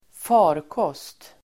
Ladda ner uttalet
farkost substantiv, craft , vessel , ship Uttal: [²f'a:rkås:t] Böjningar: farkosten, farkoster Synonymer: fordon, skepp, åkdon Definition: fortskaffningsmedel i vatten eller luft Sammansättningar: rymdfarkost (spaceship)